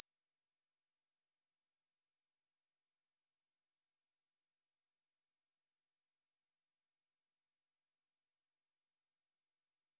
Split analogico a 16500 hz
16500hz.wav